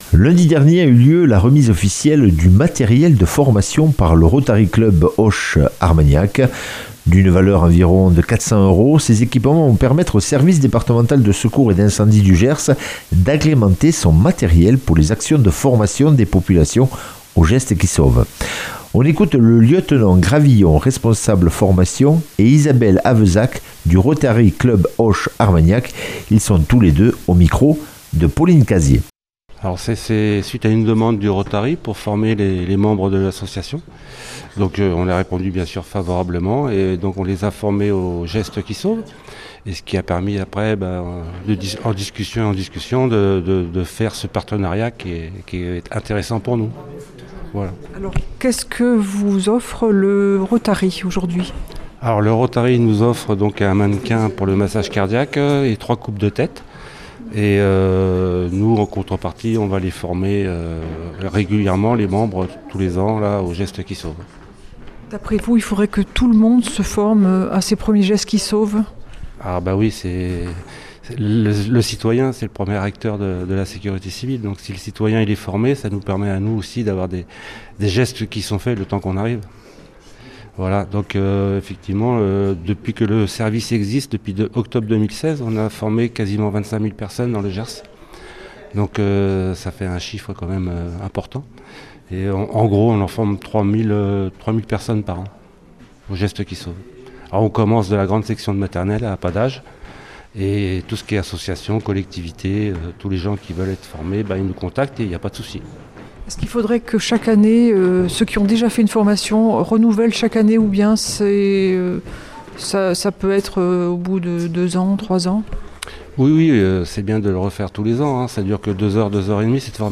mercredi 11 février 2026 Interview et reportage Durée 10 min